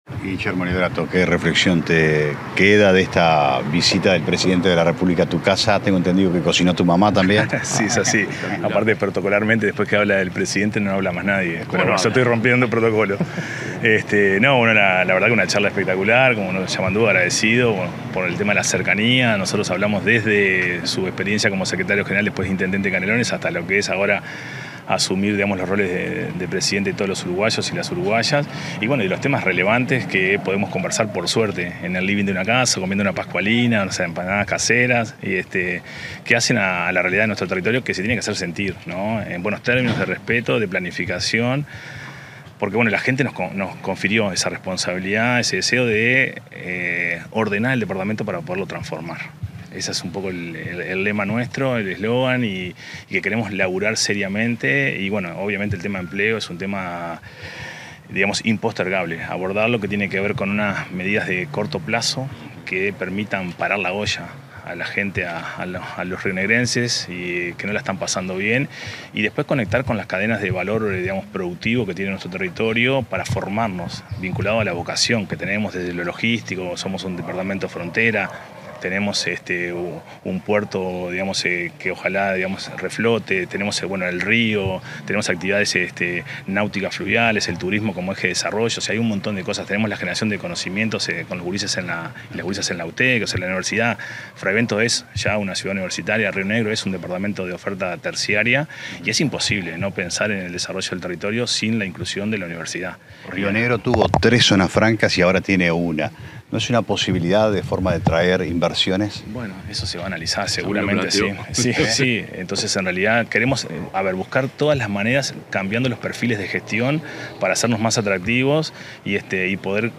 Declaraciones del intendente electo de Río Negro, Guillermo Levratto
El intendente electo de Río Negro, Guillermo Levratto, dialogó con la prensa tras mantener una reunión con el presidente de la República, profesor